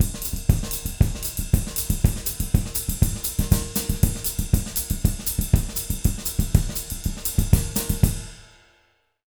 240SAMBA02-R.wav